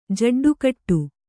♪ jaḍḍu kaṭṭu